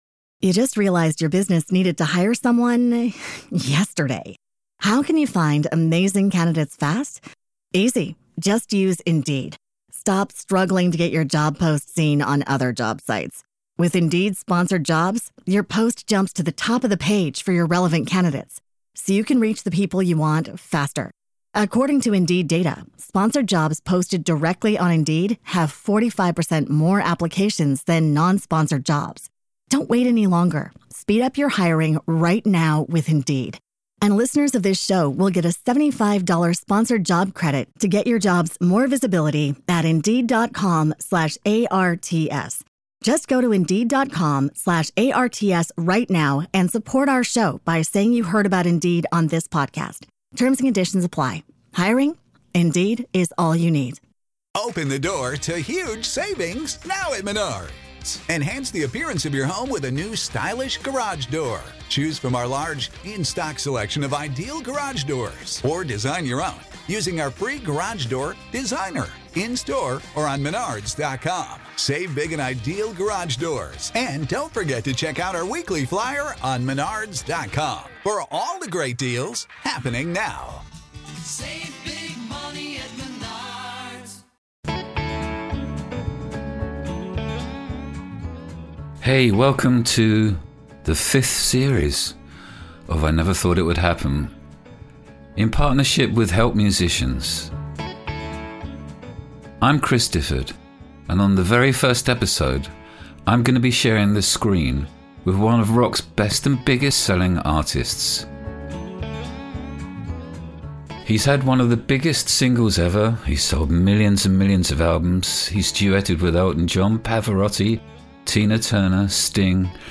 In this conversation you'll hear about Bryan's songwriting start with his first musical partner Jim Vallance, their work on the 'Pretty Woman' musical, the amazing story about his long lost, but recently returned, first guitar and duetting with Pavarotti. Plus there's also a fascinating diversion into photography.